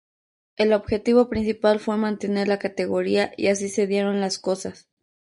man‧te‧ner
Pronounced as (IPA)
/manteˈneɾ/